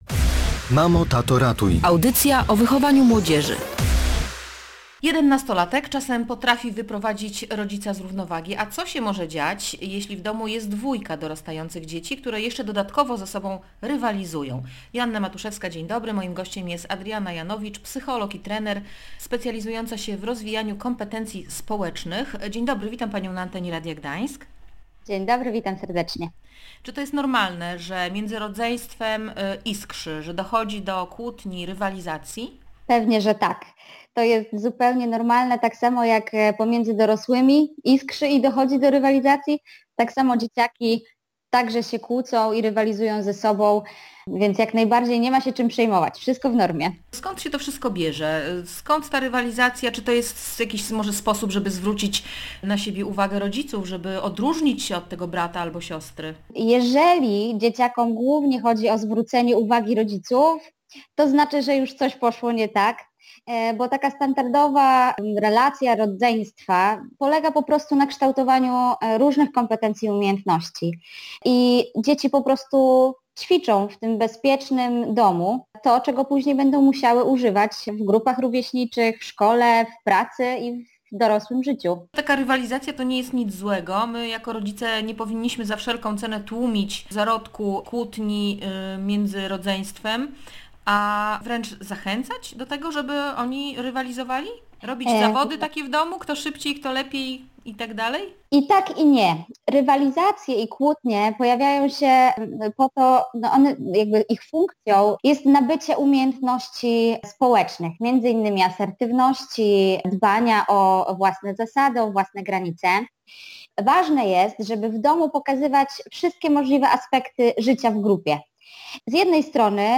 Konflikty między rodzeństwem to nauka relacji i radzenia sobie z problemami. Rodzic ma ważną rolę do odegrania, mówiła w Radiu
W rozmowie